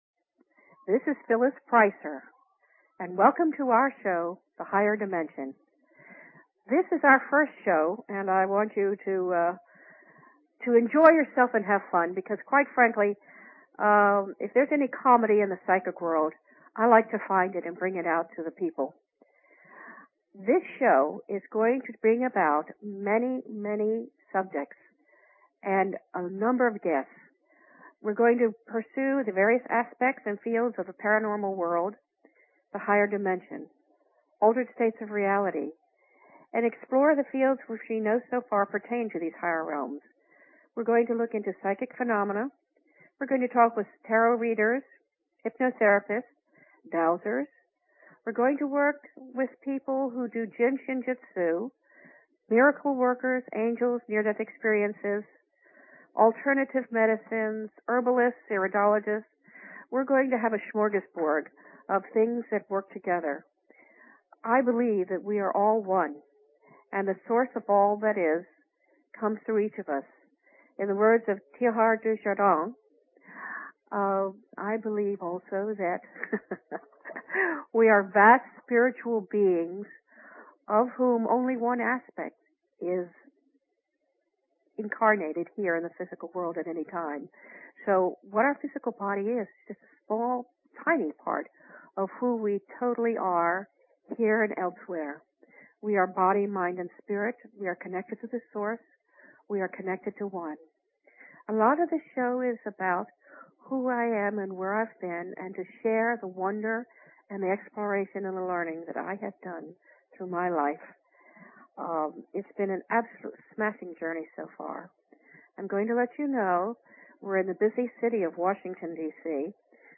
Talk Show Episode, Audio Podcast, Higher_Dimensions and Courtesy of BBS Radio on , show guests , about , categorized as